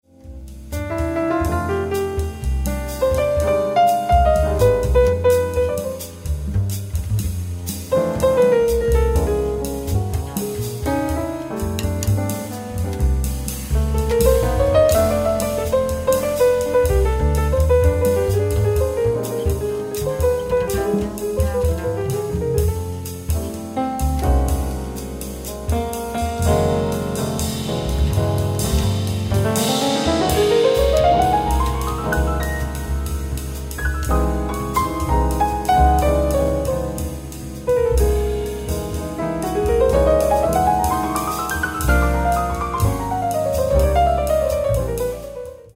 bass flute